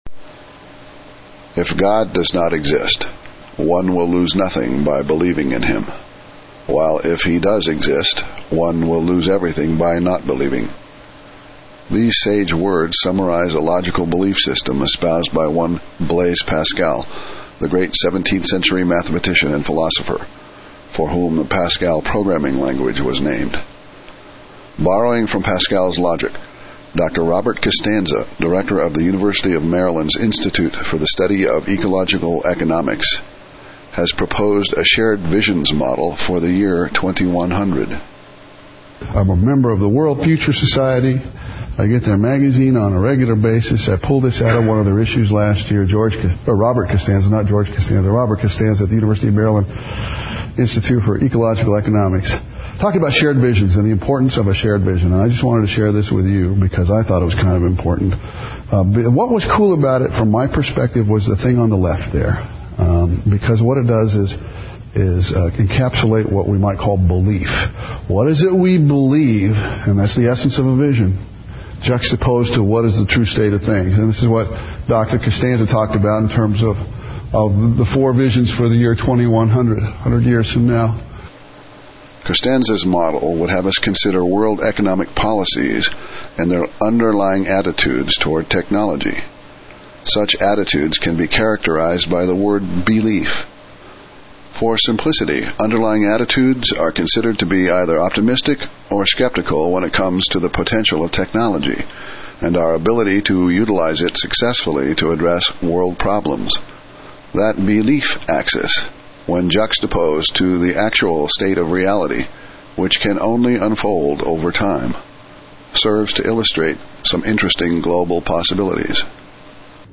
the Spoken Word